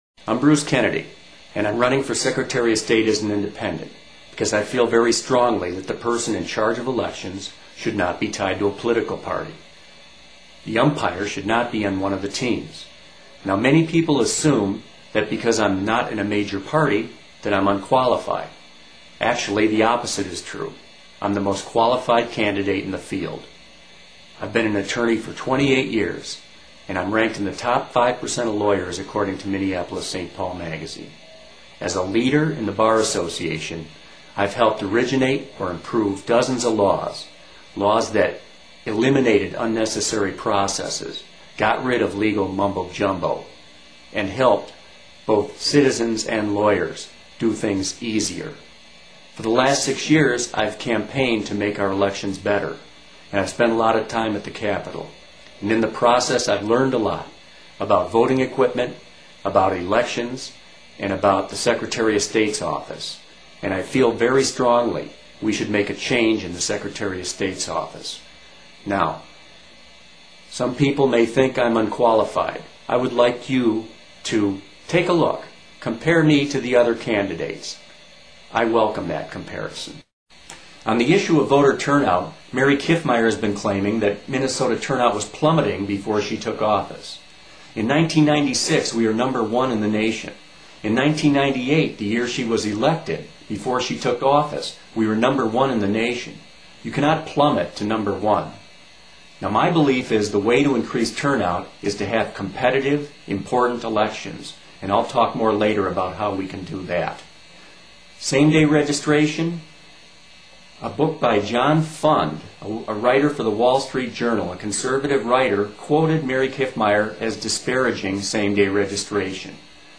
I decided to create my own responses to the debate questions. I couldn't follow the format exactly, because of the rebuttals allowed, the questions between candidates, etc. So I quickly taped my answers to many of the same questions and have posted them here.